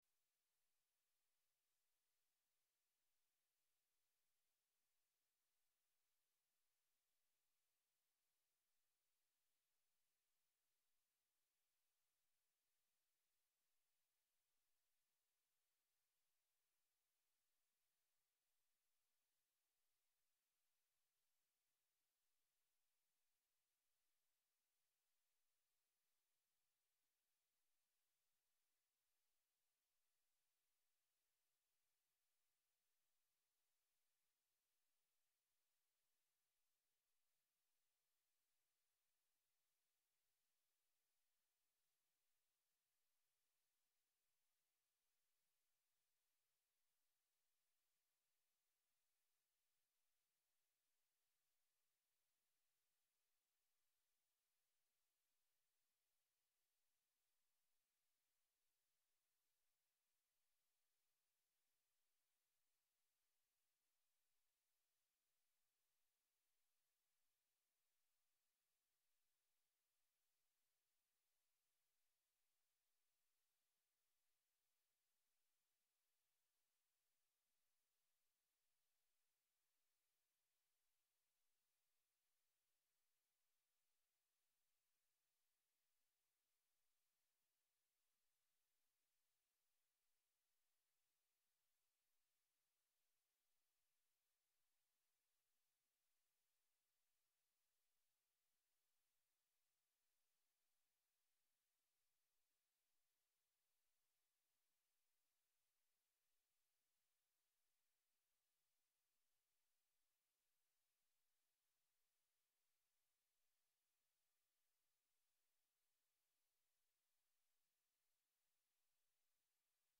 Nouvelles locales - 2 mai 2023 - 12 h